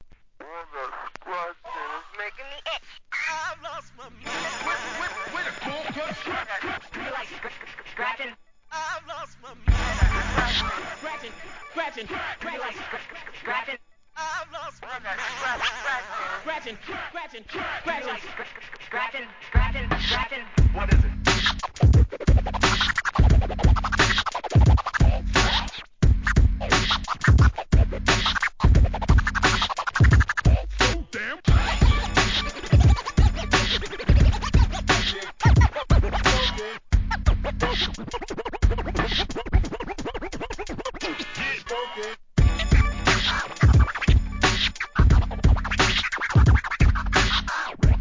HIP HOP/R&B
ブレイクビーツ、ターンテーブリスト